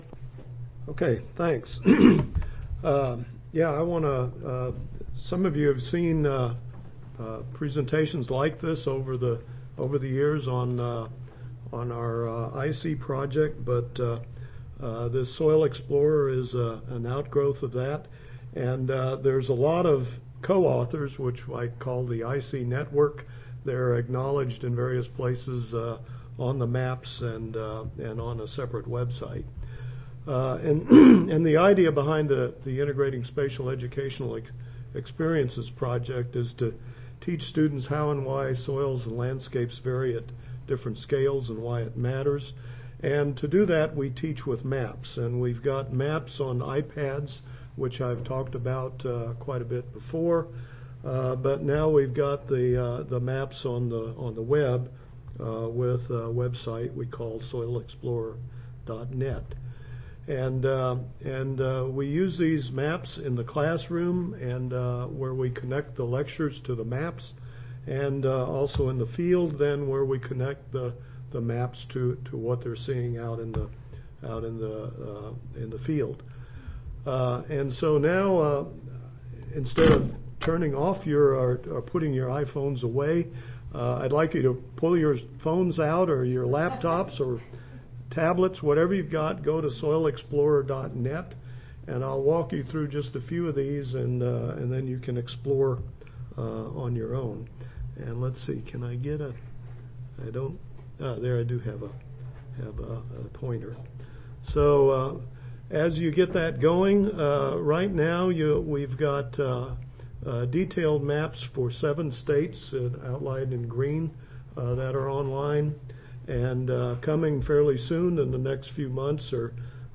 Oral Session
Audio File Recorded Presentation